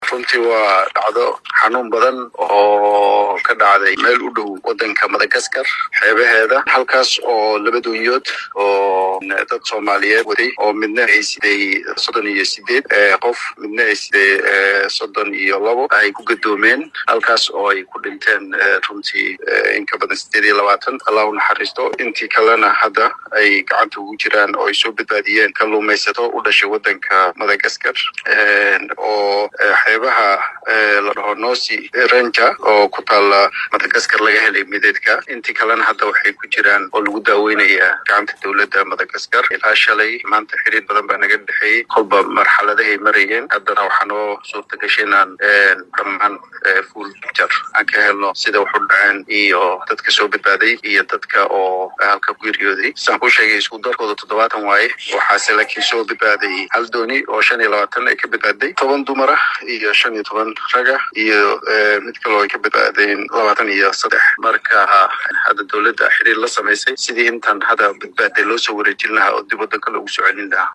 Safiirka Midowga Afrika u fadhiya Jamhuuriyadda Federaalka Soomaaliya, Cabdullahi Warfaa oo la hadlay saxaafadda ayaa faah-faahin ka bixiyay doonyo siday in